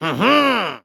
Minecraft Version Minecraft Version 25w18a Latest Release | Latest Snapshot 25w18a / assets / minecraft / sounds / mob / vindication_illager / celebrate2.ogg Compare With Compare With Latest Release | Latest Snapshot